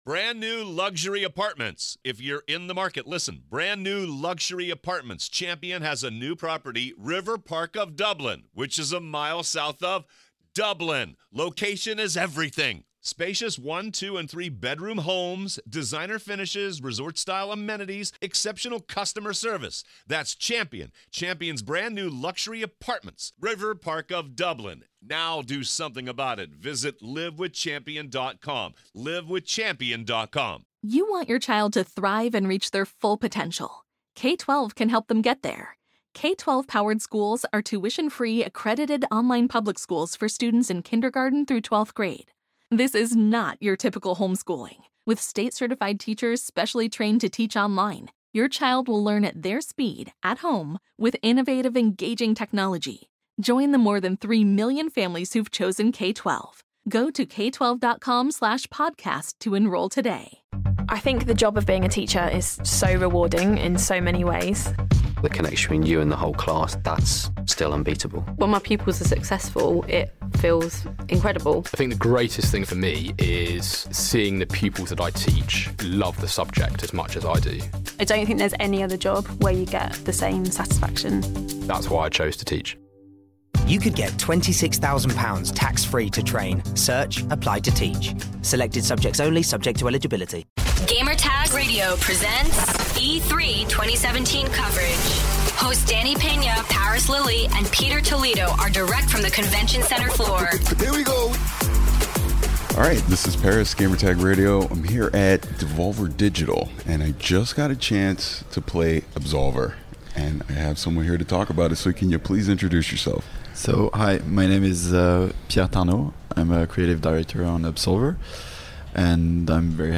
E3 2017: Absolver Interview